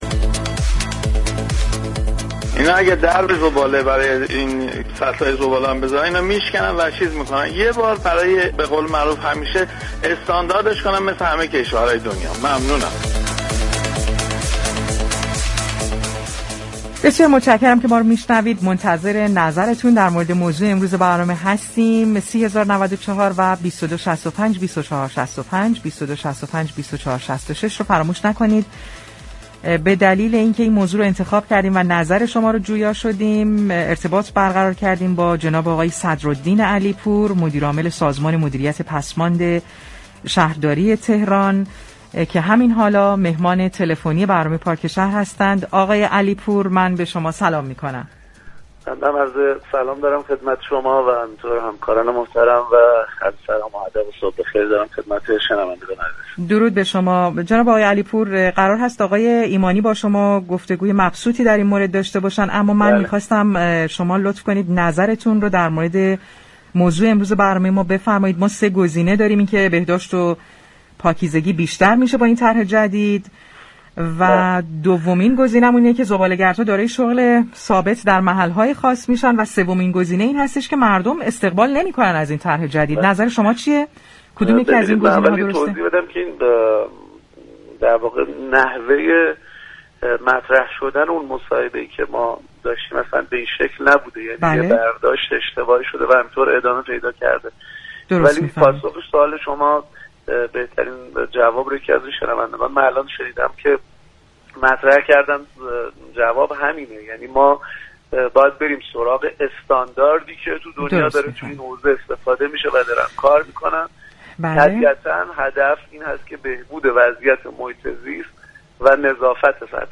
به گزارش پایگاه اطلاع رسانی رادیو تهران، صدرالدین علی‌پور مدیرعامل سازمان مدیریت پسماند شهرداری تهران در گفتگو با پارك شهر رادیو تهران در خصوص طرح جامع مدیریت پسماند و هدف از اجرای این طرح، گفت: ما باید به دنبال استانداردهای جهانی باشیم در هیچ كجای دنیا جمع‌آوری موقت پسماند از طریق مخازن سرباز مرسوم نیست، ولی متأسفانه در كشورمان 15 سال است كه پسماند به این شیوه جمع‌آوری می‌شود و در هر ساعتی از روز می‌توان در این مخازن زباله ریخت.